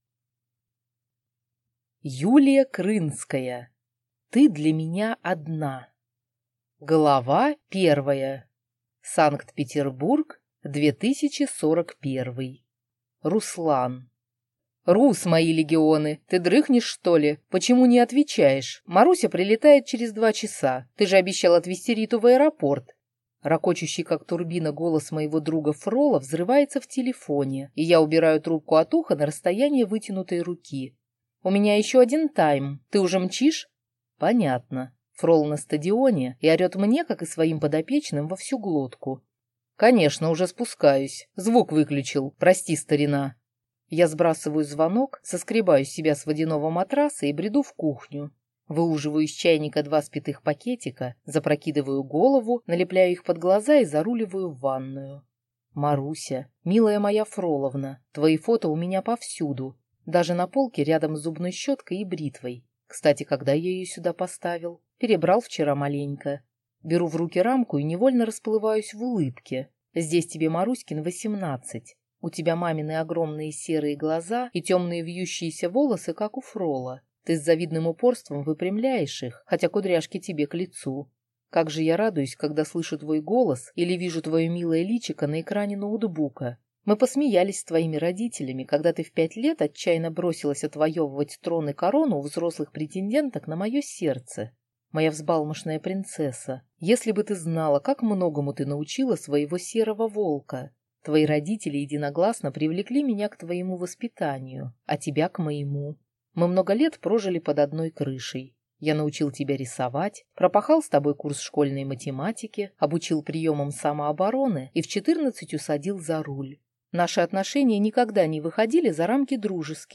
Аудиокнига Ты для меня одна | Библиотека аудиокниг